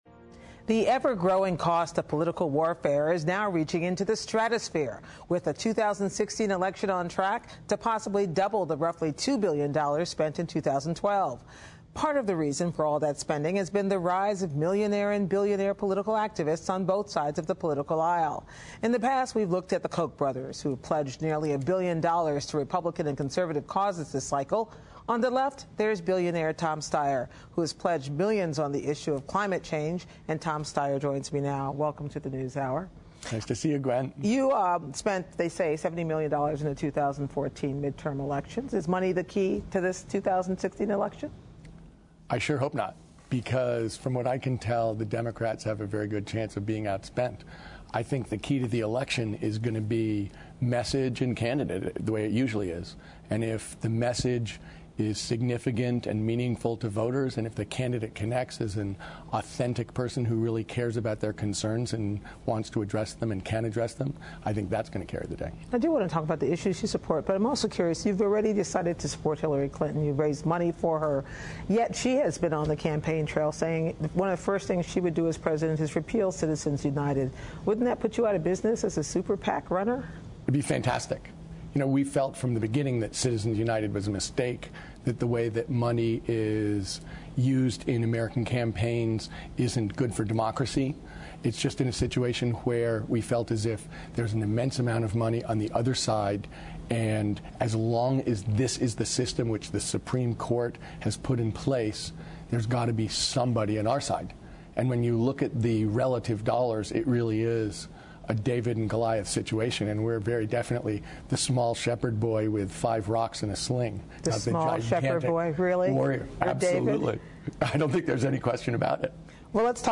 One reason is the rise of spending by millionaire and billionaire political activists on both sides of the aisle. Gwen Ifill talks to billionaire Tom Steyer of NextGen Climate, who has pledged millions on the issue of climate change.